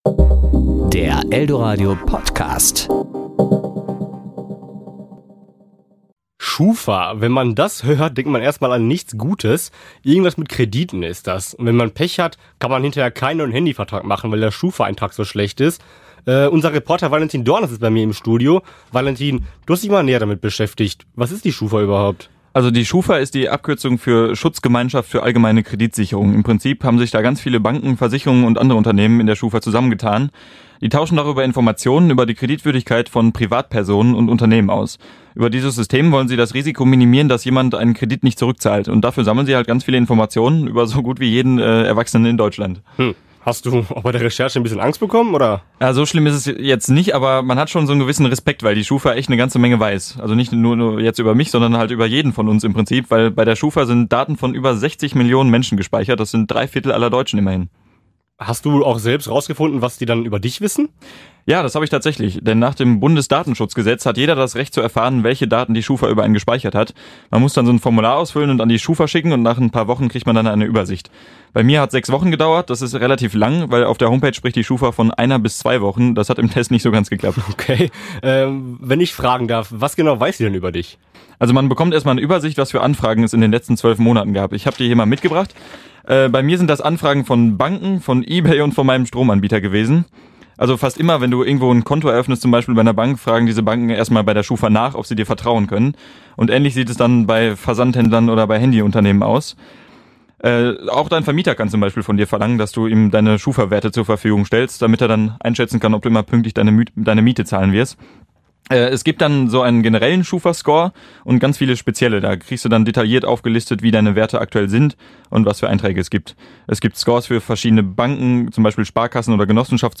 Serie: Kollegengespräch  Sendung: Toaster